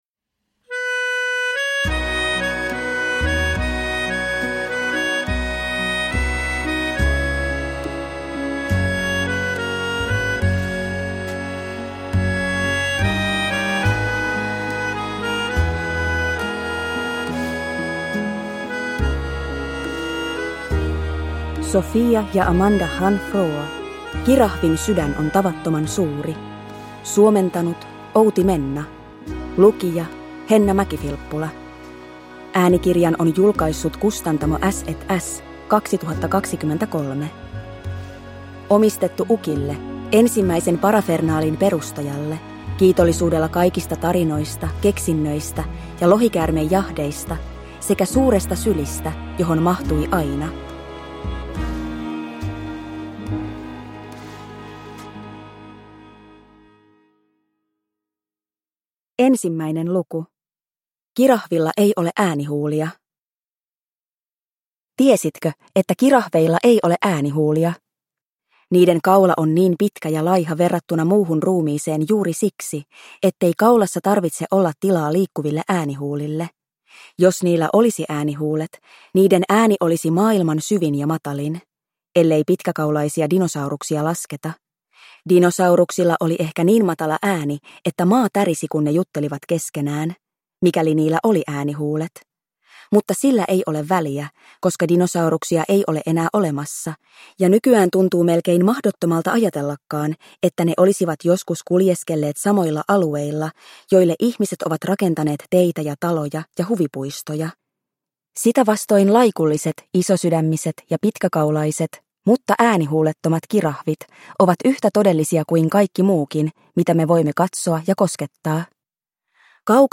Kirahvin sydän on tavattoman suuri – Ljudbok – Laddas ner